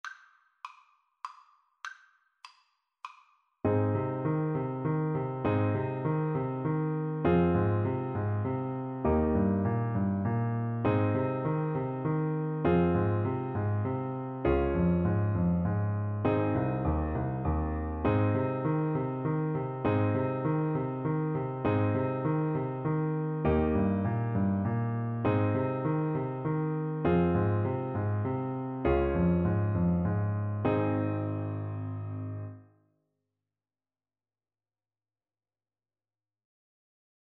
Play (or use space bar on your keyboard) Pause Music Playalong - Piano Accompaniment Playalong Band Accompaniment not yet available reset tempo print settings full screen
"Arirang" is a Korean folk song, often considered as the unofficial national anthem of Korea.
Ab major (Sounding Pitch) F major (Alto Saxophone in Eb) (View more Ab major Music for Saxophone )
3/4 (View more 3/4 Music)